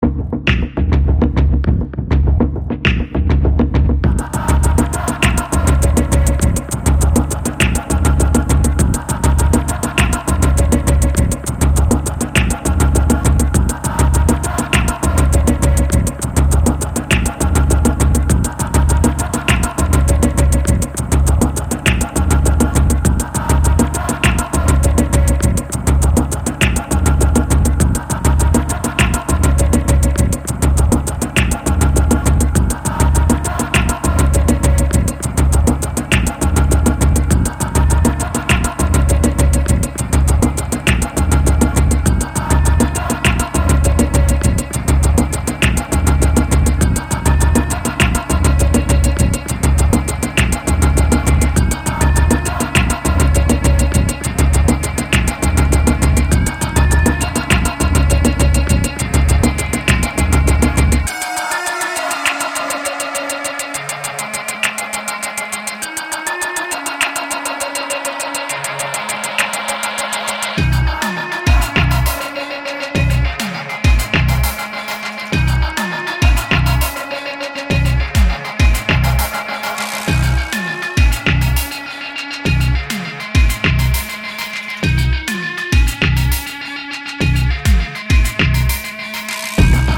Eclectic leftfield melting pot of techno flavours.
Techno